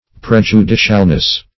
Prej`u*di"cial*ness, n.